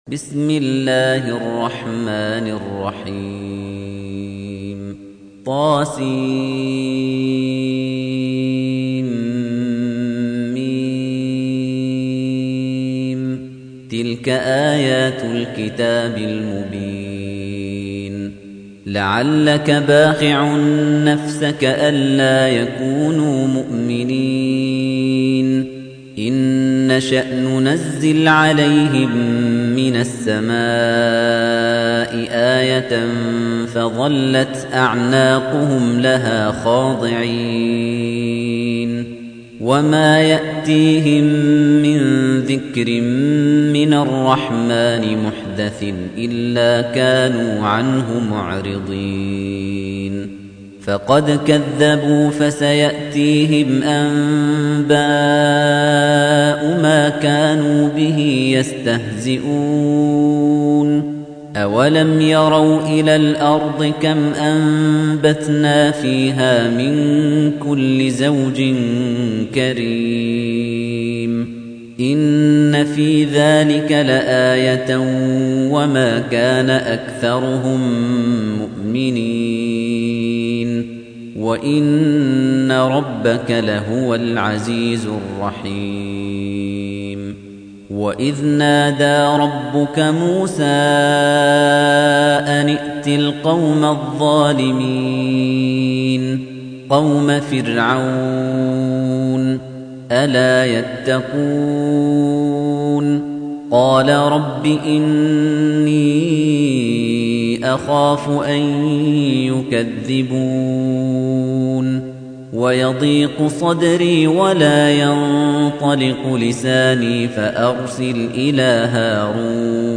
تحميل : 26. سورة الشعراء / القارئ خليفة الطنيجي / القرآن الكريم / موقع يا حسين